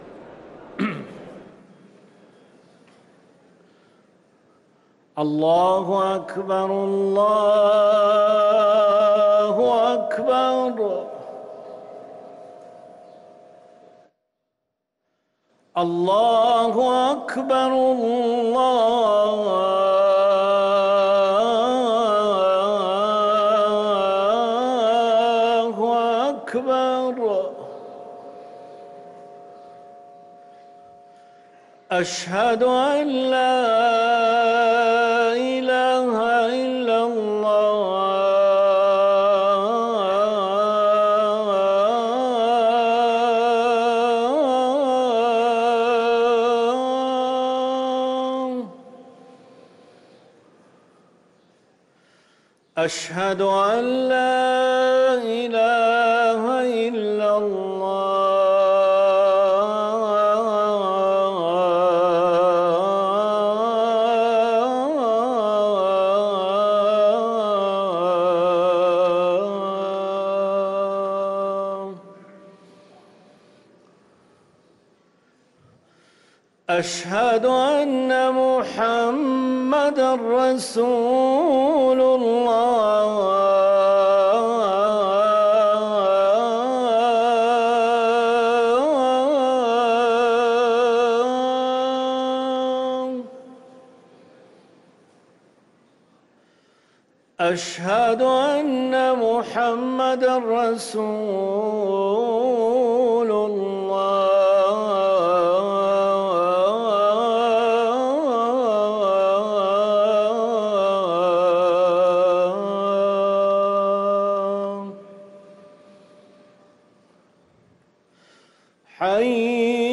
أذان المغرب للمؤذن